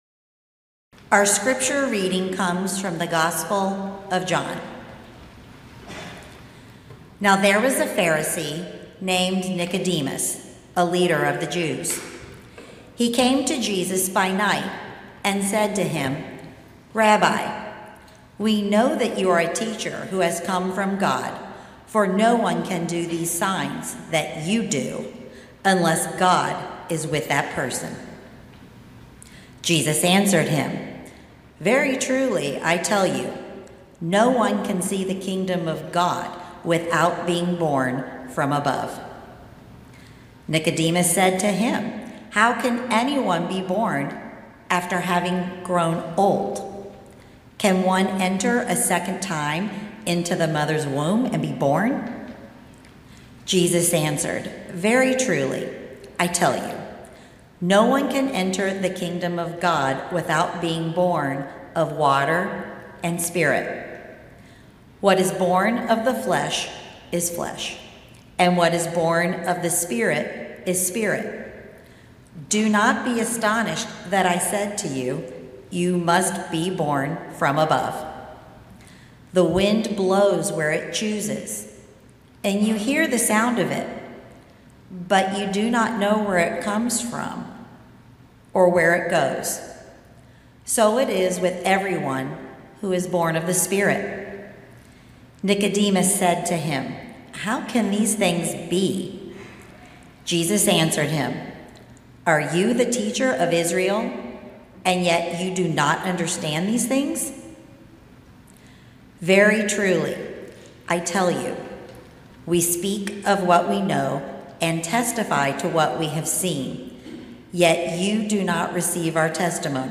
Today, as a part of our Lent series on understanding Jesus, I’m going to preach the first of a couple sermons about who Jesus is in his ministry and what kind of teaching he does. Today we will meet Nicodemus, who is struggling to be free of expectations and perceptions others may have of him.